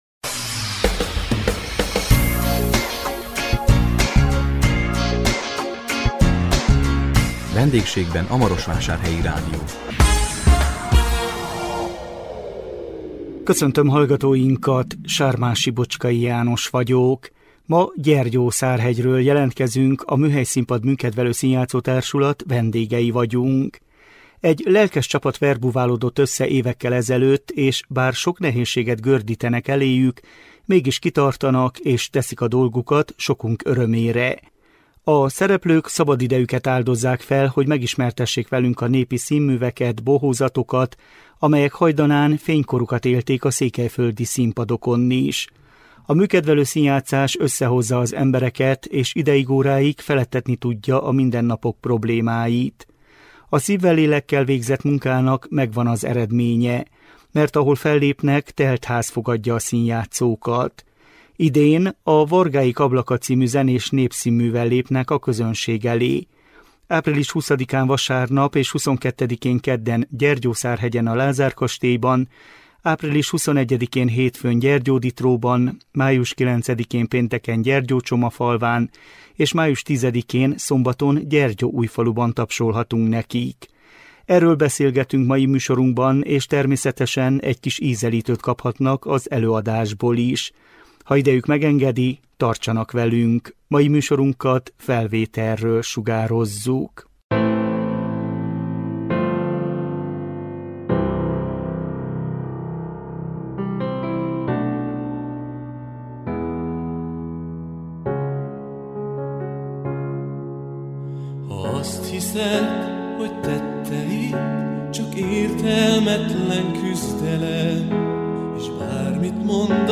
A 2025 április 17-én közvetített VENDÉGSÉGBEN A MAROSVÁSÁRHELYI RÁDIÓ című műsorunkkal Gyergyószárhegyről jelentkeztünk, a Műhelyszínpad műkedvelő színjátszótársulat vendégei voltunk.